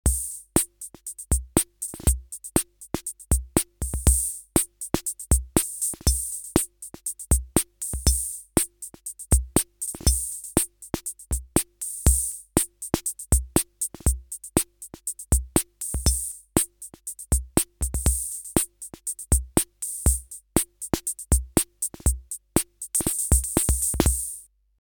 Example of CR-78’s sound – the interface works in “Note” mode: